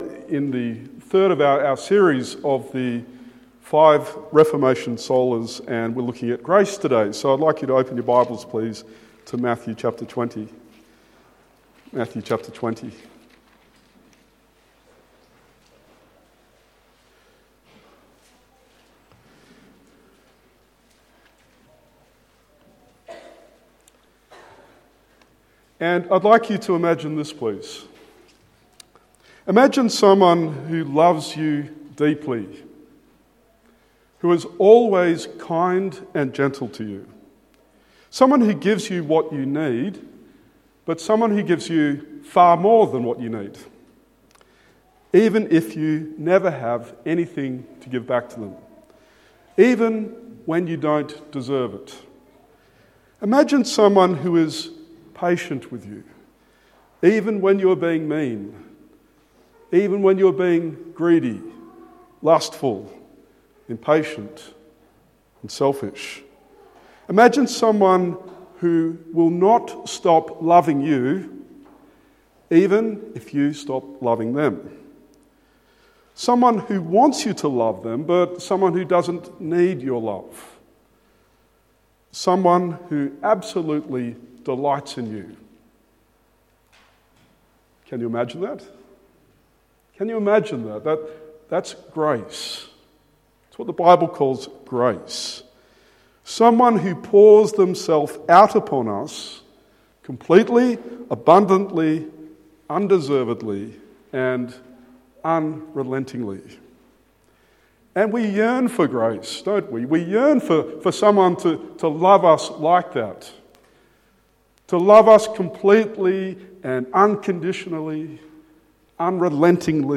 Matthew 20:1-16 Sermon